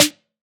SOUTHSIDE_snare_saturater.wav